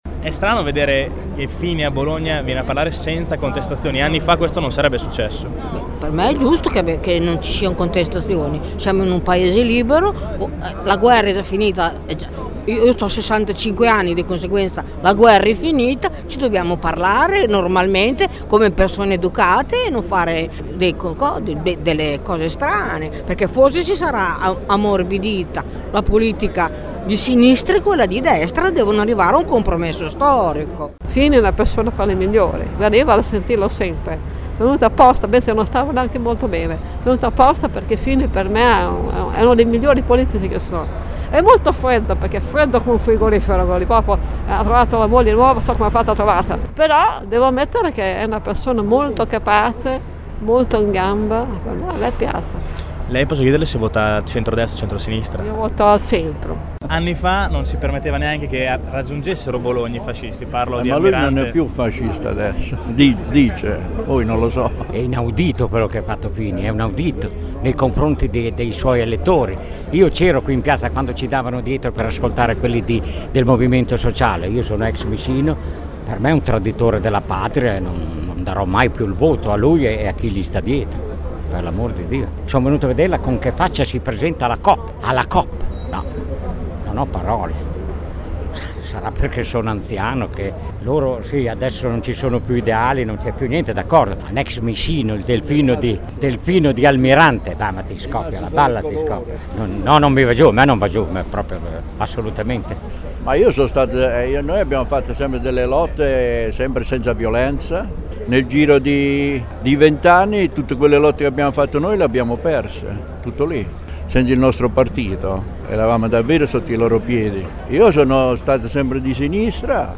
Abbiamo raccolto alcune opinioni dei cittadini che hanno assistito all’incontro: dalle loro voci non sembra che sia accaduto granchè, anzi.
All’uscita dell’Ambasciatori: